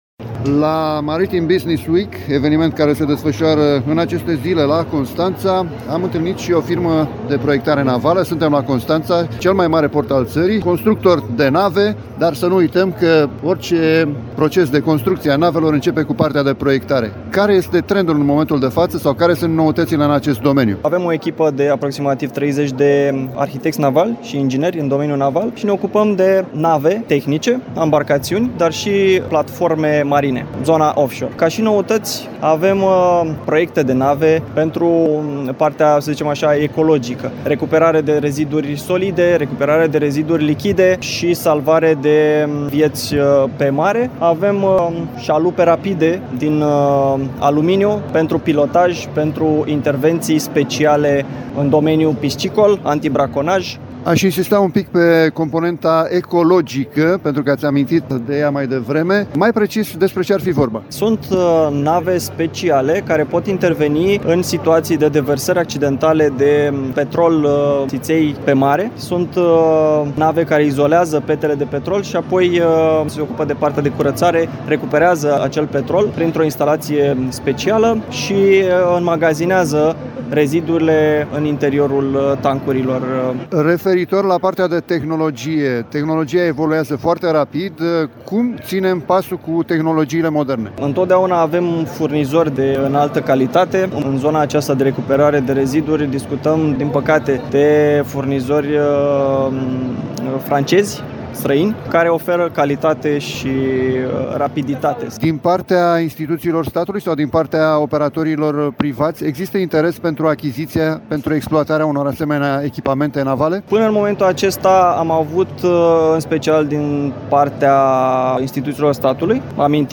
La Maritime Business Week, un eveniment dedicat portului, navelor și serviciilor din domeniu naval, ce se desfășoară în aceste zile la Constanța